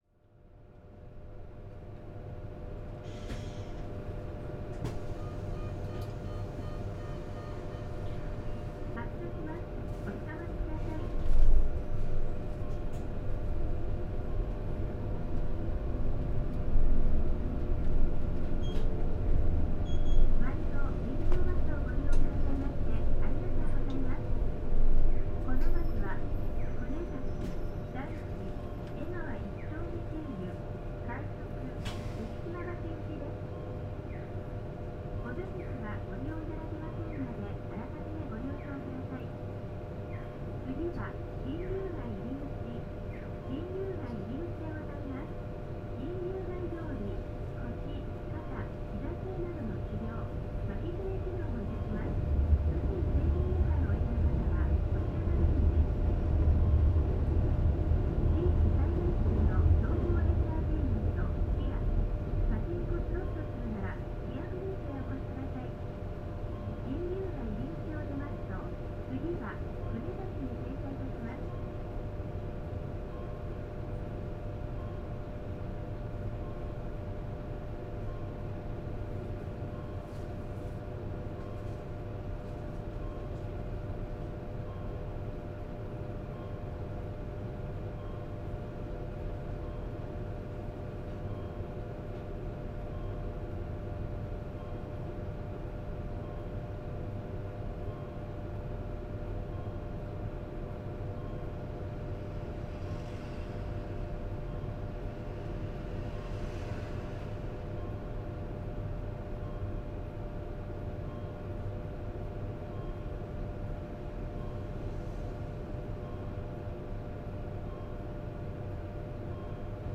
川崎鶴見臨港バス いすゞ PJ-LV234L1 ・ 走行音(全区間) (27.6MB★) 収録区間：水江町線 川21系統 水江町→川崎駅前 塩浜営業所所属のエルガノンステップ車である。